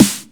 snare 1.wav